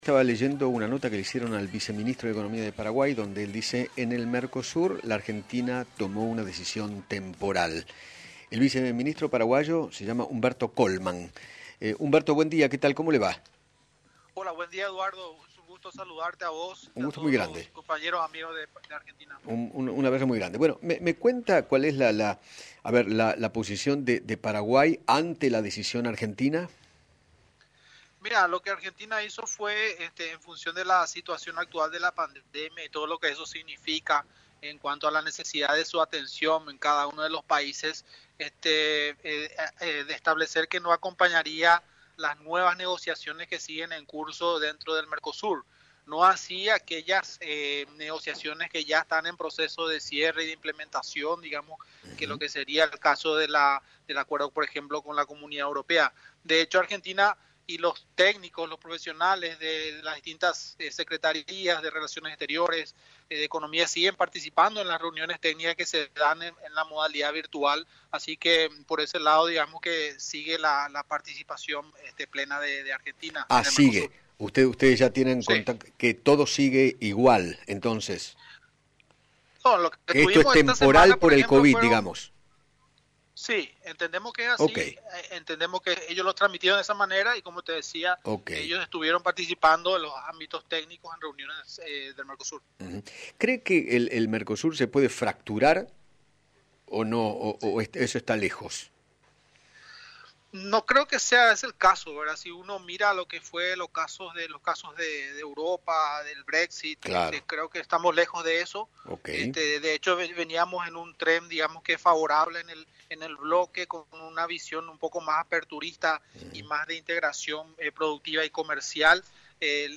Humberto Colmán, viceministro de Economía de Paraguay, dialogó con Eduardo Feinmann sobre la decisión de Argentina de retirarse de las negociaciones del Mercosur y aseguró que “es temporal”.